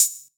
HAT-143.wav